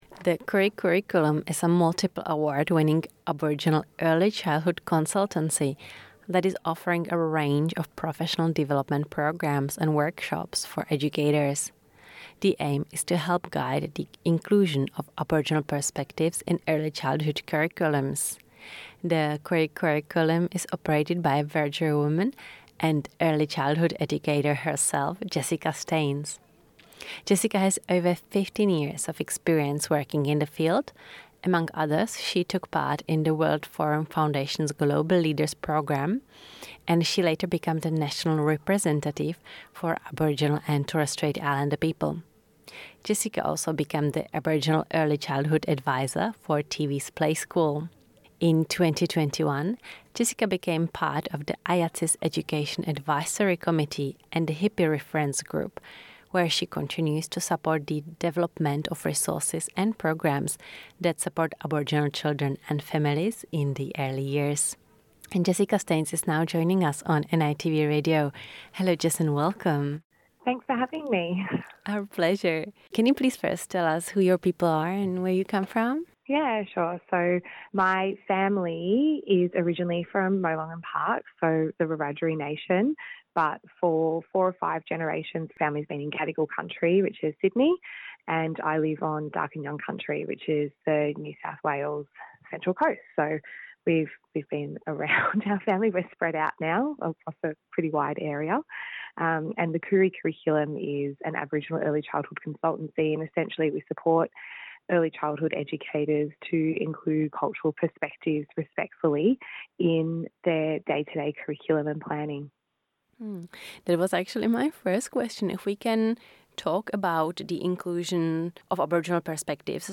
In an interview for NITV Radio